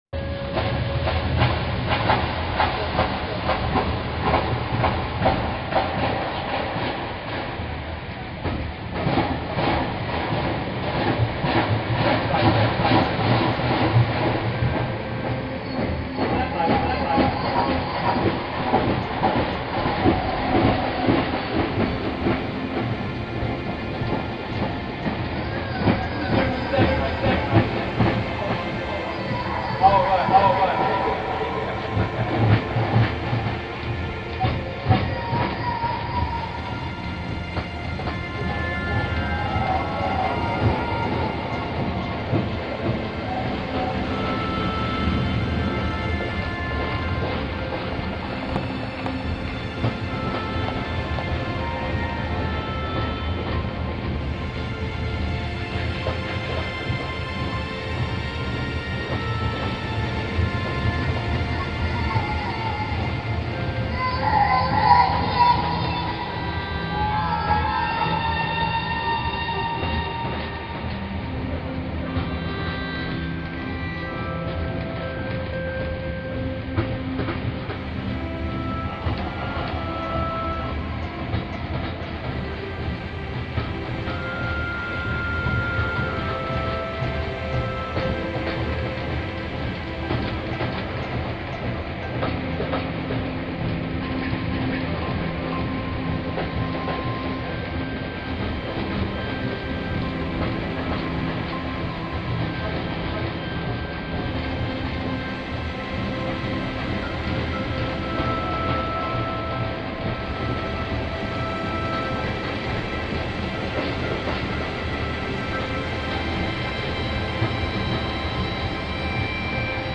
Steam (instrumental) - Original location recording made at the Bluebell Line railway, 20th August 2011.
When I got back out of curiosity I started fiddling around with effects just to see what came. After that it seemed a natural step to put orchestration on top of it.